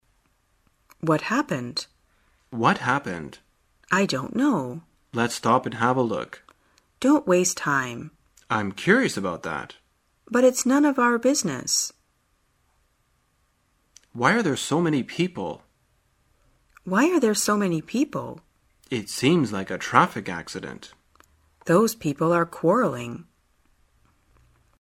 在线英语听力室生活口语天天说 第68期:怎样了解街头突发事件的听力文件下载,《生活口语天天说》栏目将日常生活中最常用到的口语句型进行收集和重点讲解。真人发音配字幕帮助英语爱好者们练习听力并进行口语跟读。